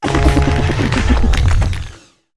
revavroom_ambient.ogg